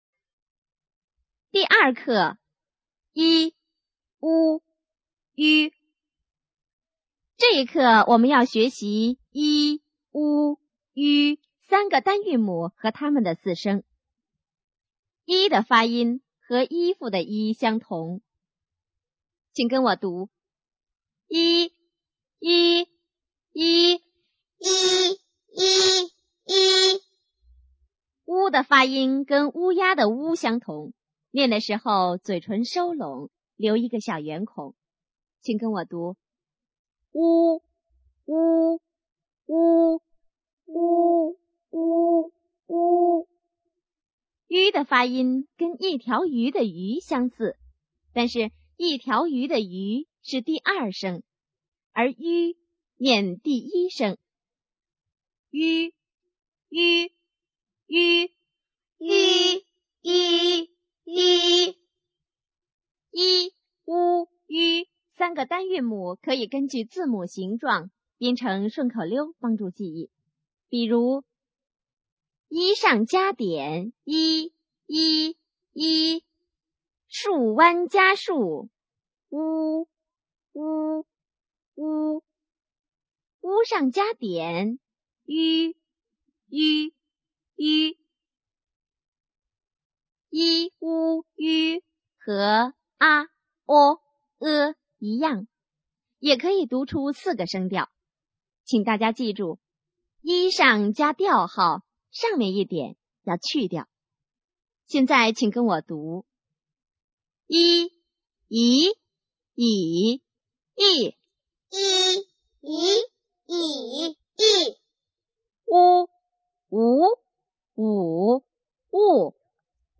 普通话拼音学习教程第二课 i u ü